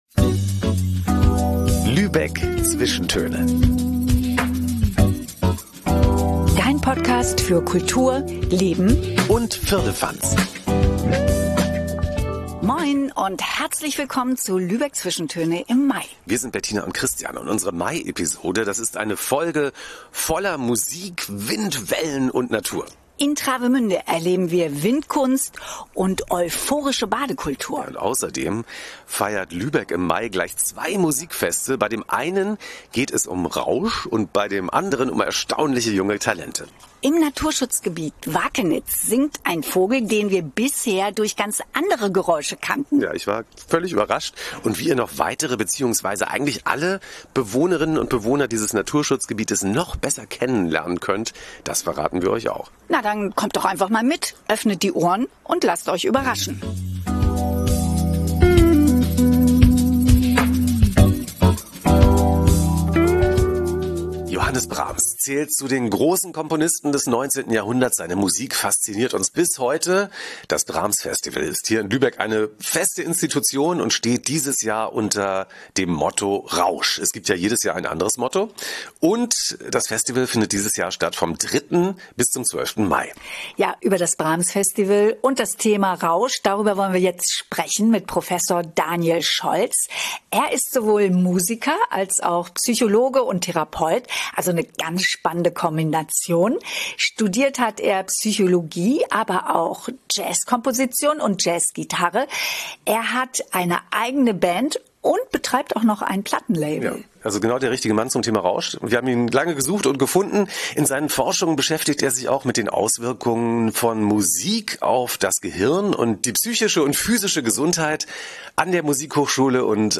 Unsere Mai-Episode ist voller Musik, Wind, Wellen und Natur.
Im Naturschutzgebiet Wakenitz haben wir einen Vogel singen hören, den wir bisher nur durch ganz andere Geräusche kannten.